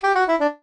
jingles-saxophone_10.ogg